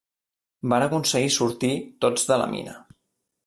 Pronounced as (IPA) [ˈmi.nə]